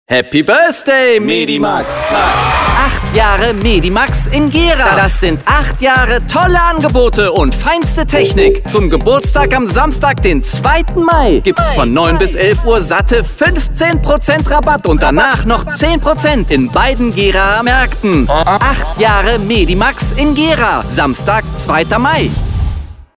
Meine Stimme klingt warm, präzise, jung, klar und facettenreich.
Sprechprobe: Werbung (Muttersprache):
Young age warm sounding, friendly and fresh voice.